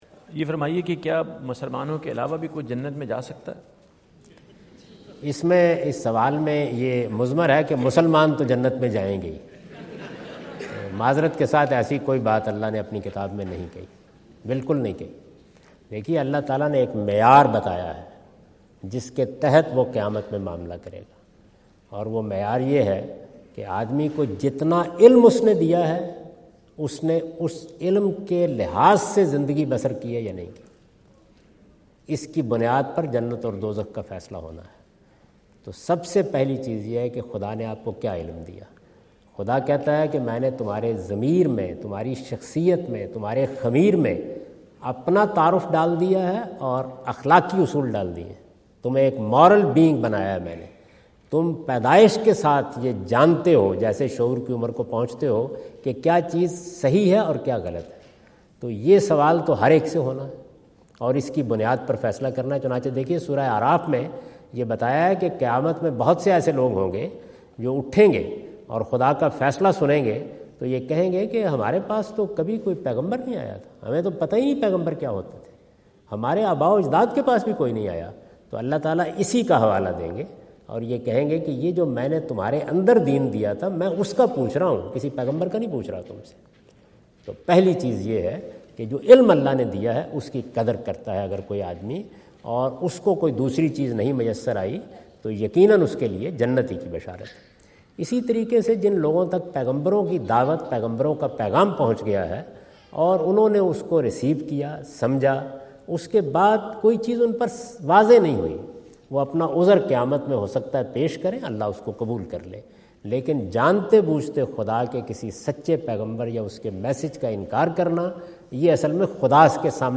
Javed Ahmad Ghamidi answer the question about "Will Non-Muslims Go to Heaven?" asked at North Brunswick High School, New Jersey on September 29,2017.
جاوید احمد غامدی اپنے دورہ امریکہ 2017 کے دوران نیوجرسی میں "کیا غیر مسلم بھی جنت میں جائیں گے؟" سے متعلق ایک سوال کا جواب دے رہے ہیں۔